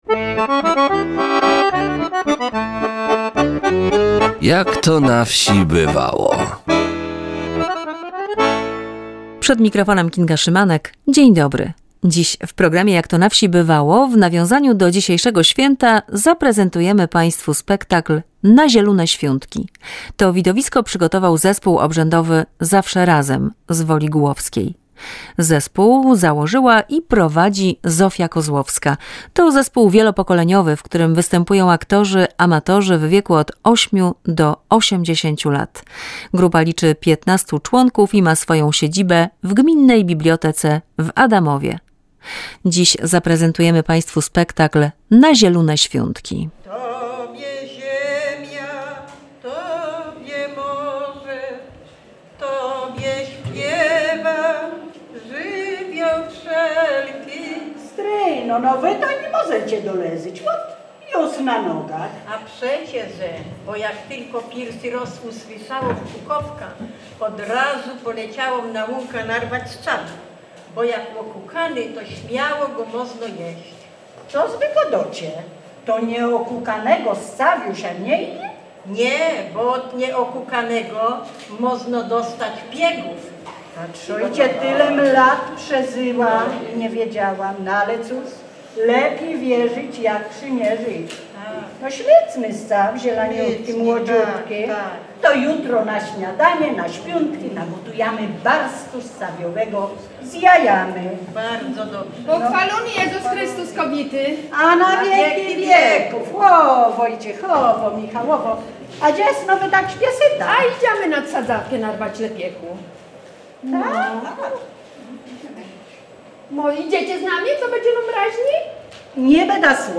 W programie zaprezentujemy widowisko obrzędowe "Na zielune świuntki" w wykonaniu Zespołu Obrzędowego Zawsze Razem z Woli Gułowskiej.
W programie zaprezentujemy widowisko obrzędowe „Na zielune świuntki” w wykonaniu Zespołu Obrzędowego Zawsze Razem z Woli Gułowskiej.